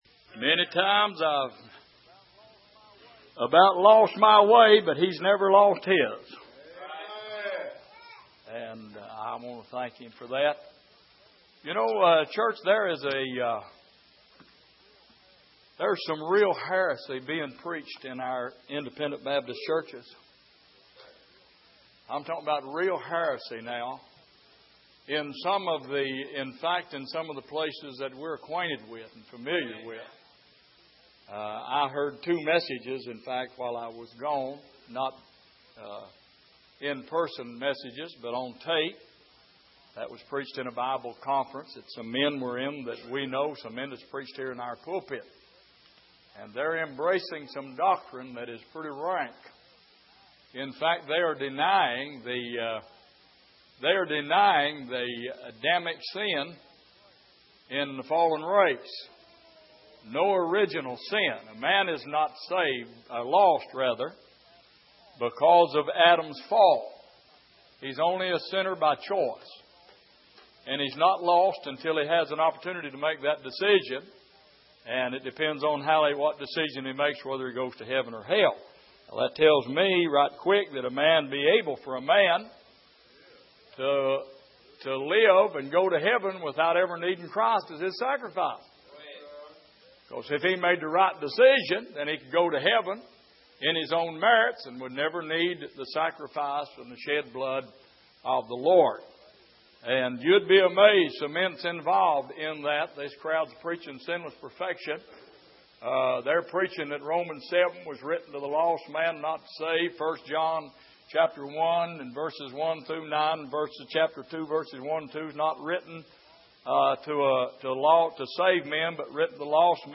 Passage: Psalm 127:1 Service: Midweek